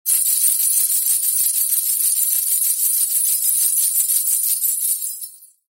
Danza árabe, bailarina haciendo el movimiento twist 03
Sonidos: Acciones humanas